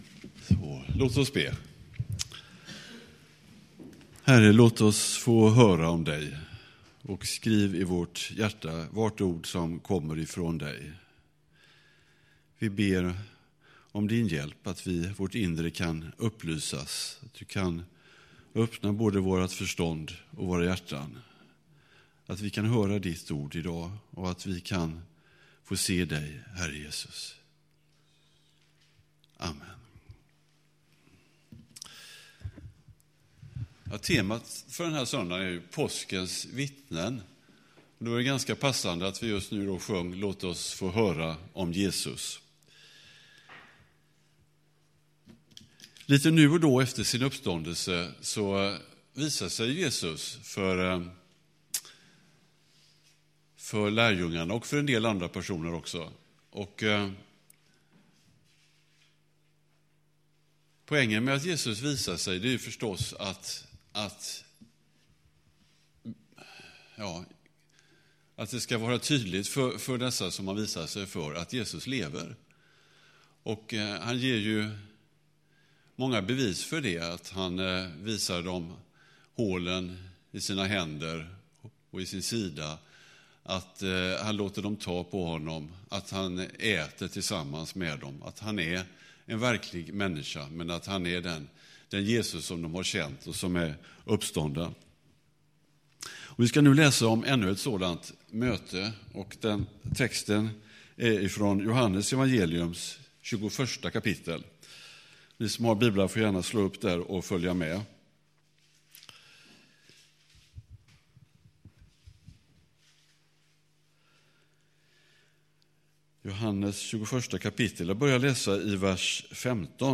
Predikan
”Älskar du mig?” (Inledning saknas)